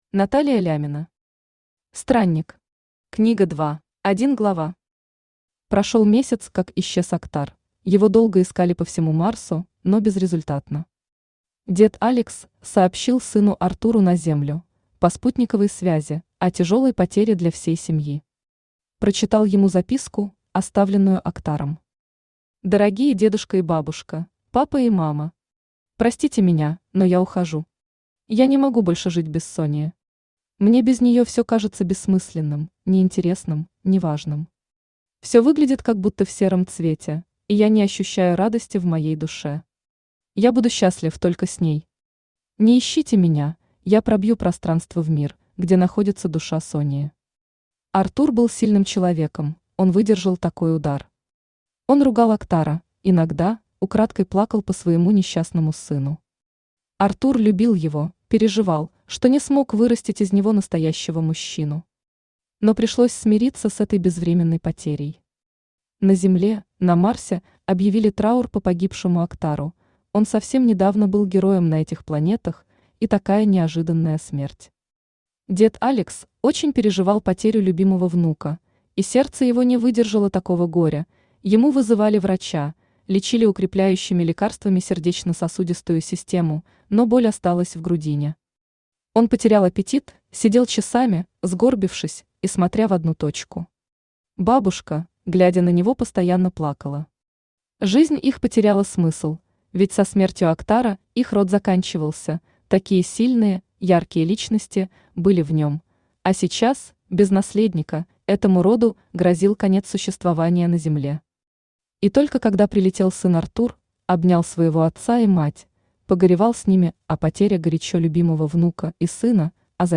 Аудиокнига Странник. Книга 2 | Библиотека аудиокниг